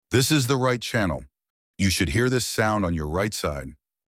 RIGHT CHANNEL